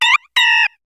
Cri de Pandespiègle dans Pokémon HOME.